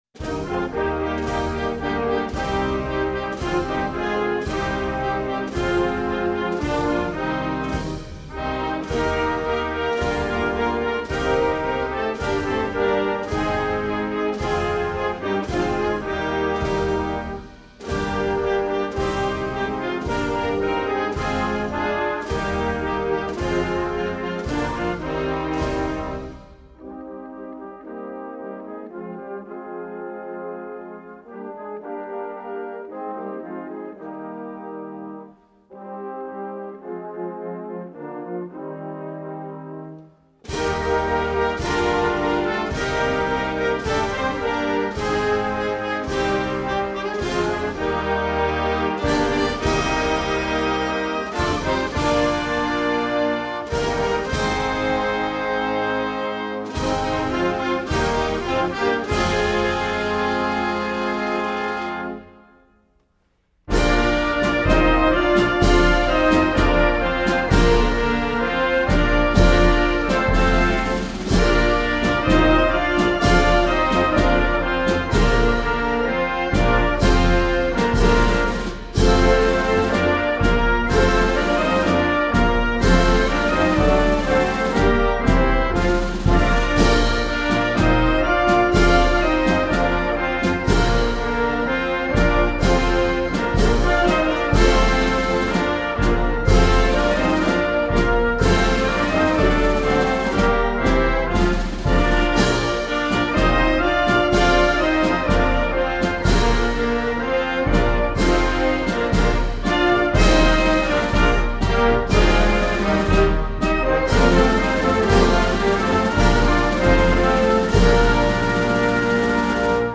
Inni per cerimoniale
A questo scopo è a disposizione di officer e soci la sequenza dei tre inni per l'anno 2025-26 (indiano, europeo e italiano) come